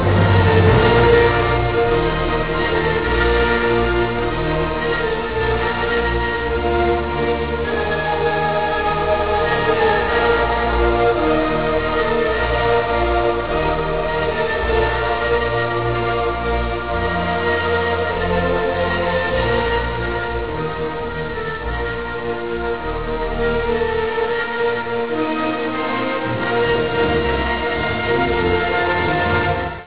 Composizione colorita, ricca di virtuosismi
Original track music: